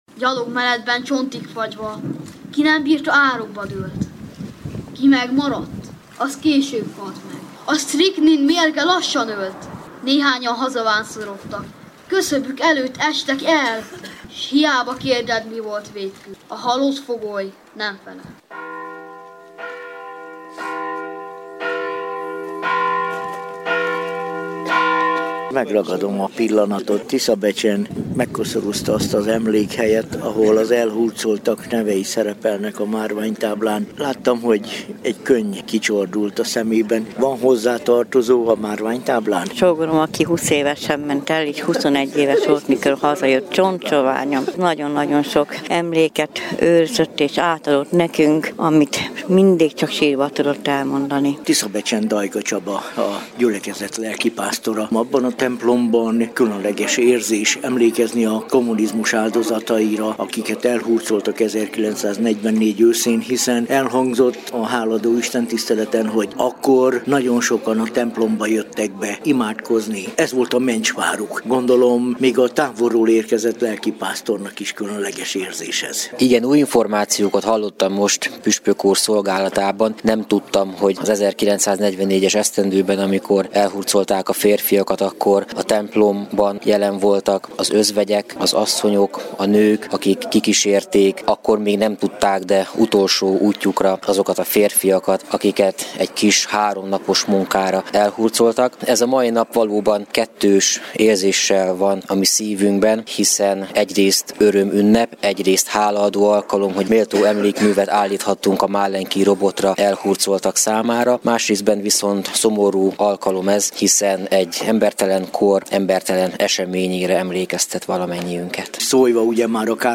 Hálaadó istentisztelet Tiszabecsen - hanganyaggal
A hálaadó istentiszteleten Fekete Károly, a Tiszántúli Református Egyházkerület püspöke hirdetett igét. A püspök a múltat felidézve a jelennek és a jövőnek szóló tanulságokról is beszélt.